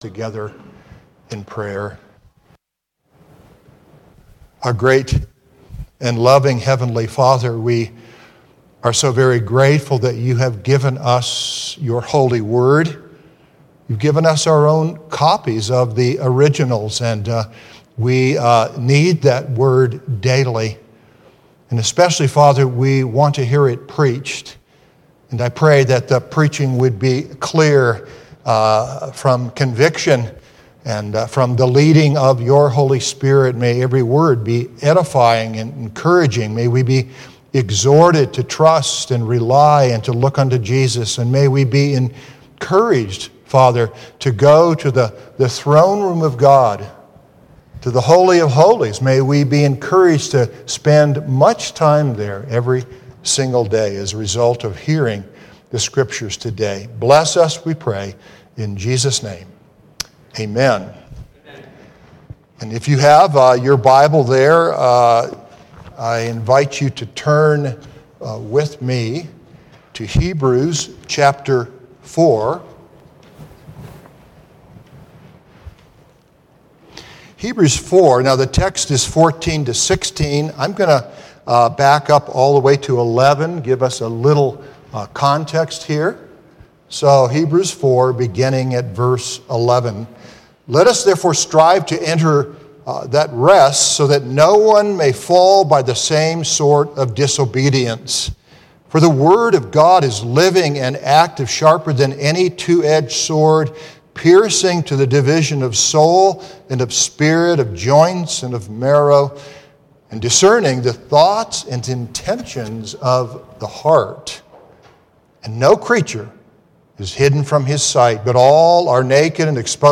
Hebrews 4:4-16 * Note - durring the service, there was an electrical outage.